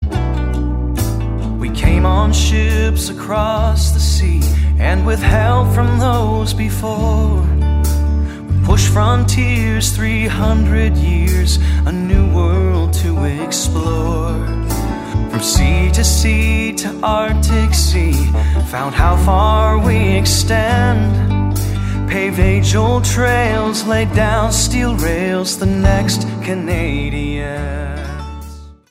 vocals & guitar
Genres: Easy Listening, Vocal, Pop-Folk